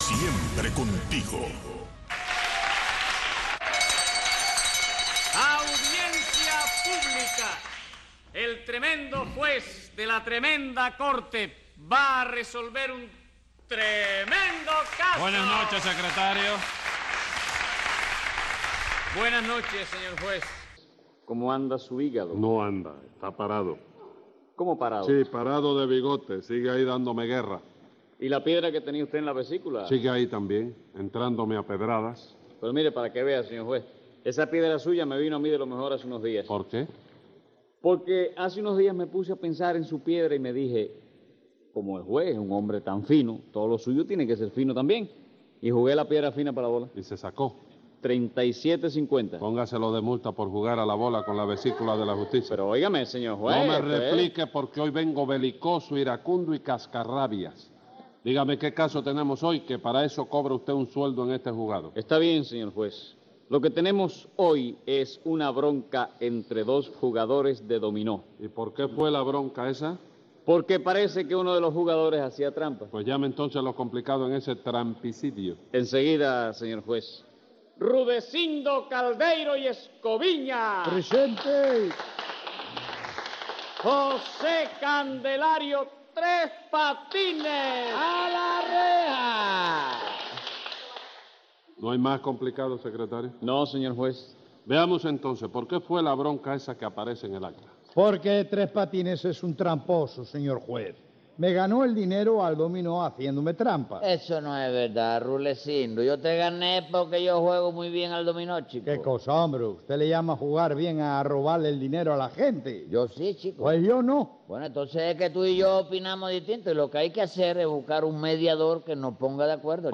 Divertidísimas situaciones en el más puro humor cubano en el legendario show de Tres Patines.